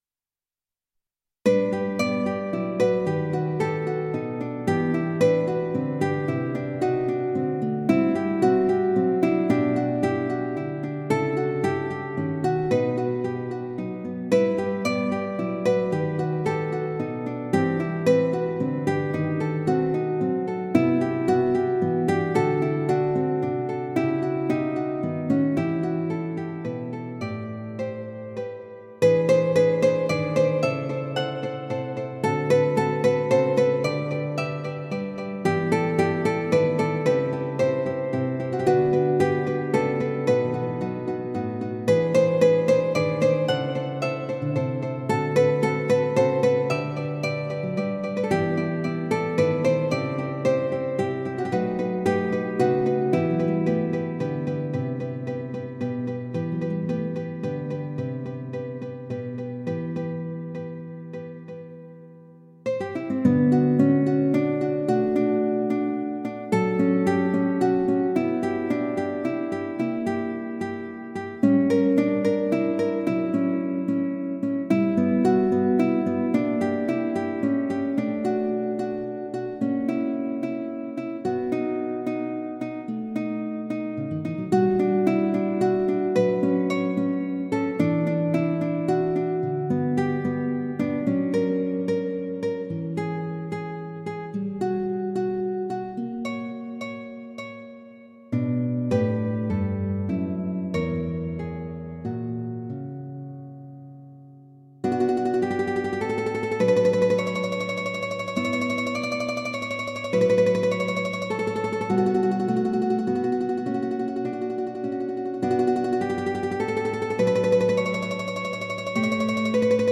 クラシック 　ＭＩＤＩ(11KB） 　YouTube
ギター独奏曲です。